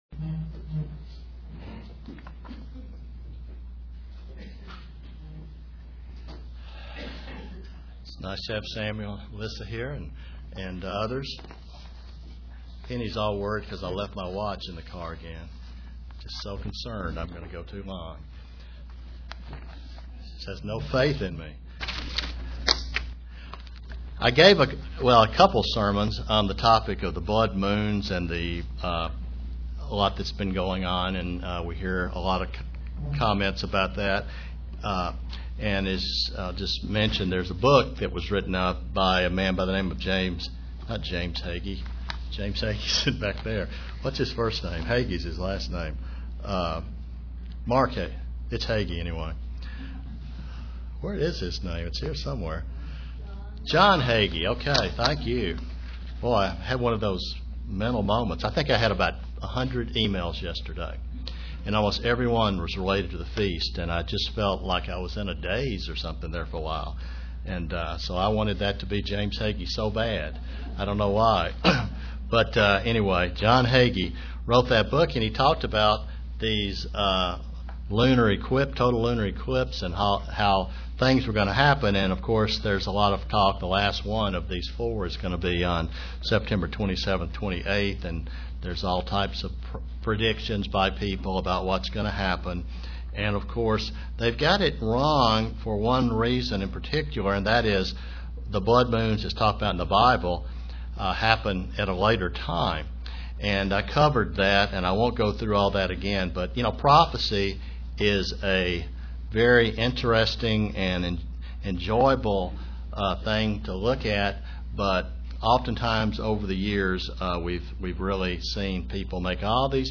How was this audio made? Given in Kingsport, TN